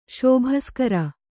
♪ śobhaskara